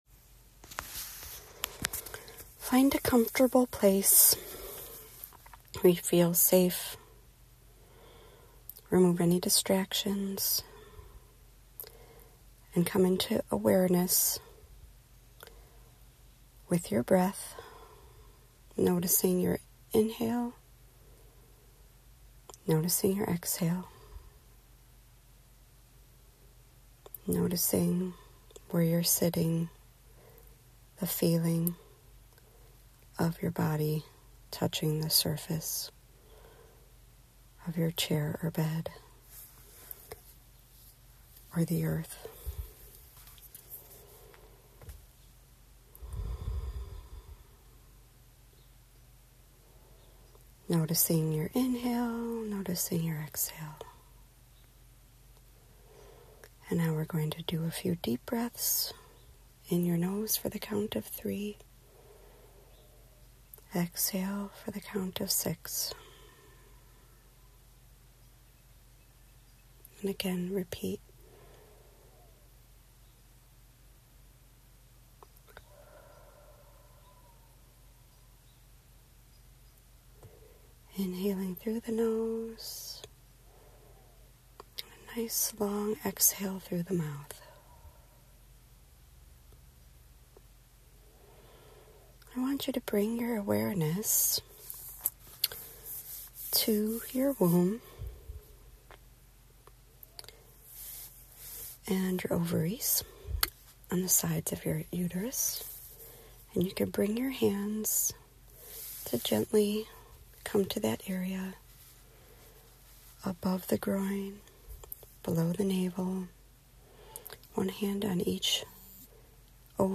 This is a guided visualization, and is inspired by the work of Tami Lynn Kent and my studies of fertility from the Chinese Medicine perspective.
FertilityVisualizationandMeditation.m4a